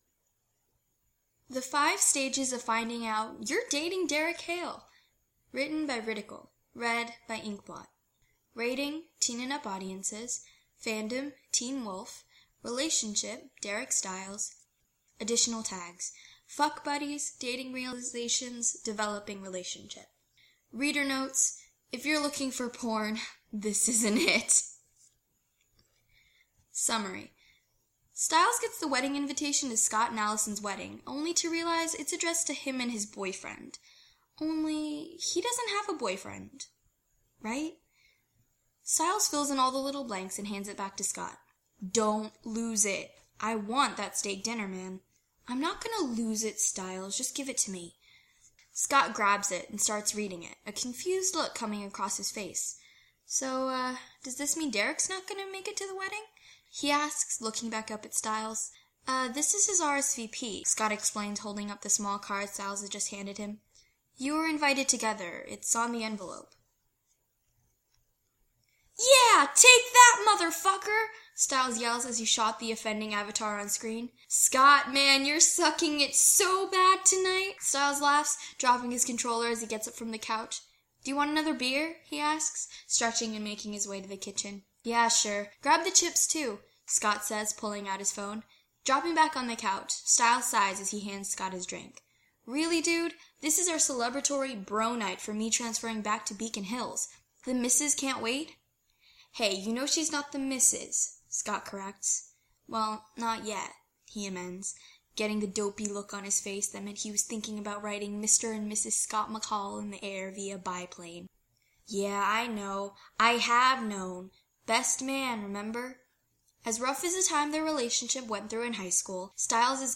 [podfic]
I recorded this a little while ago, and I didn't think to give different characters different voices.